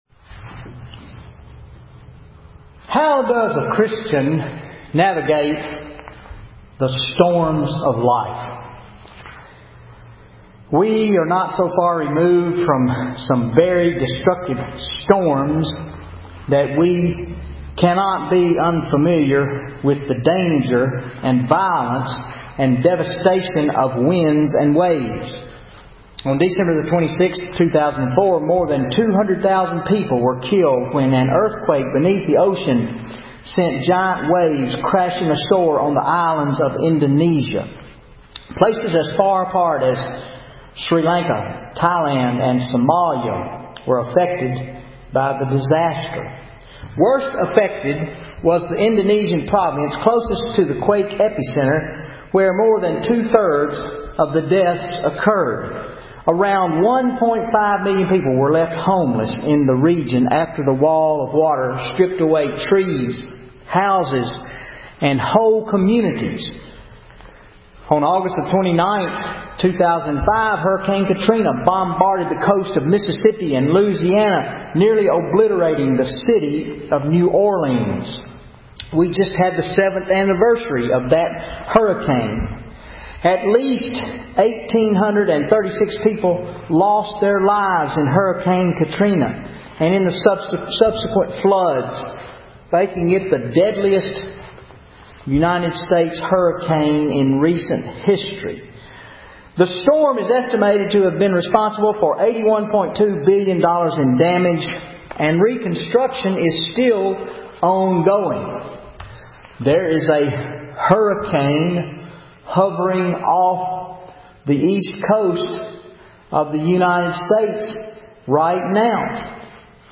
10 February 2013 Sermon Mark 9:1-13 Glory on the Mountain